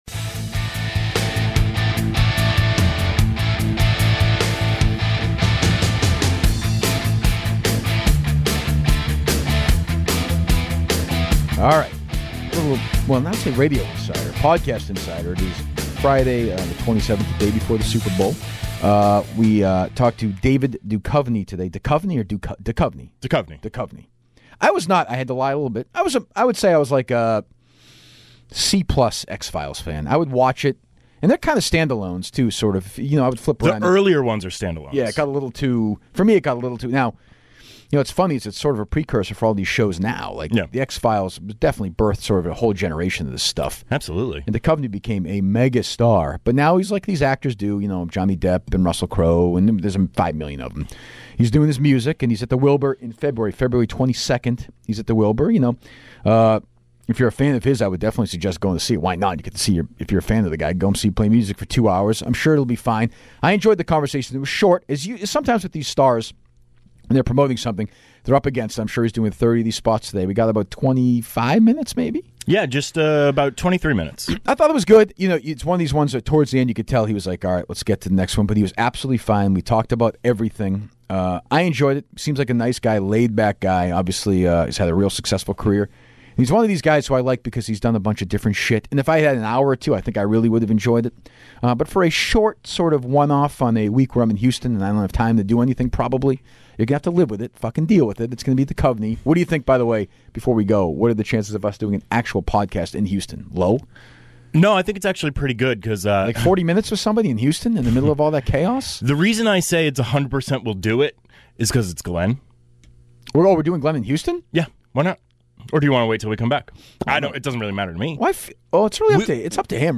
This is a fascinating discussion about show business and controlling your own career.